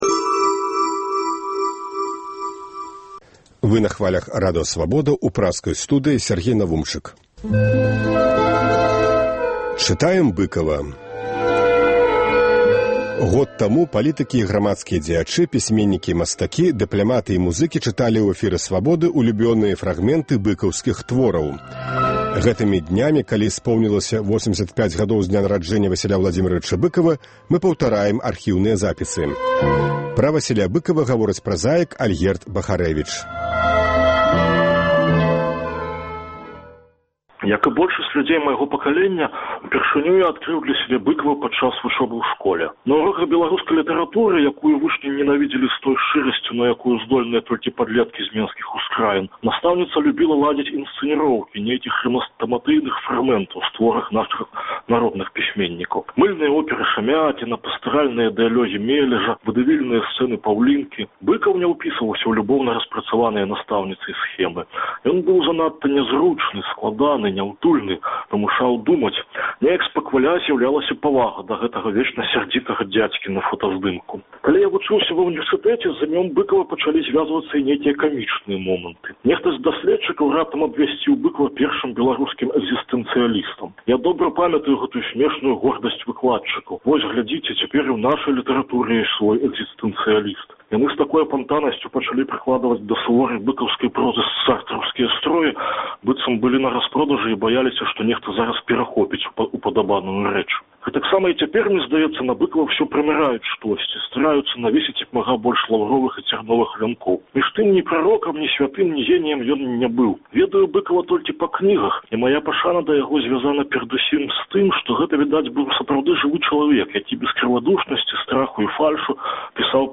Гэтымі днямі, калі споўнілася 85-гадоў з дня нараджэньня Васіля Быкава, мы паўтараем архіўныя запісы. Normal 0 Сёньня Быкава чытаюць празаікі Ўладзімер Дамашэвіч і Альгерд Бахарэвіч.